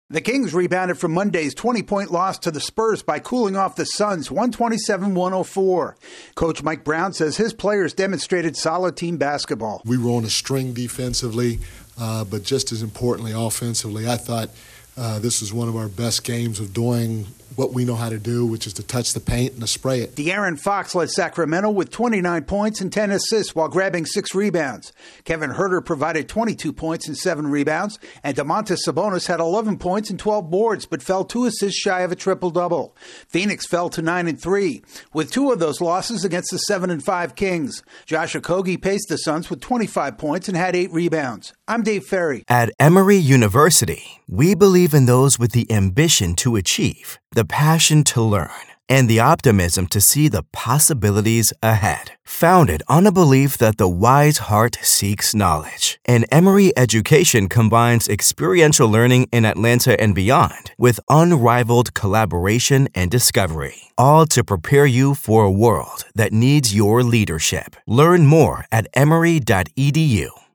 The Kings knock off the Suns for the second time in four days. AP correspondent